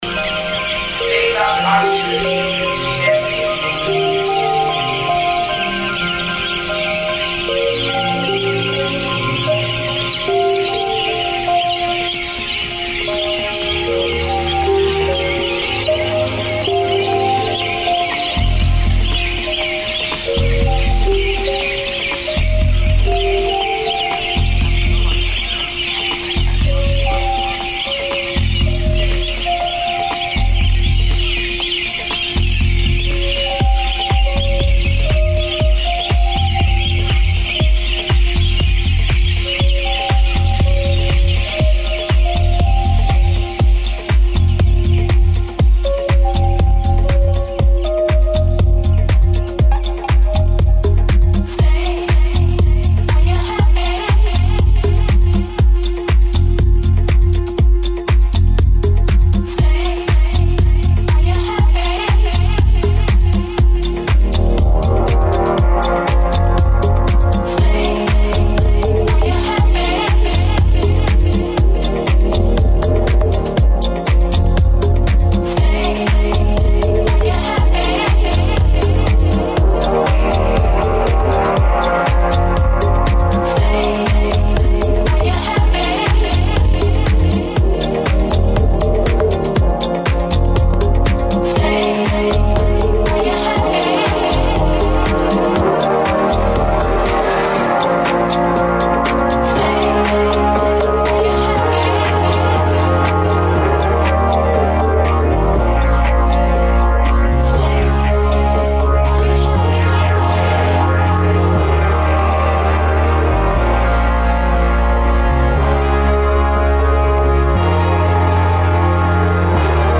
GUEST MIX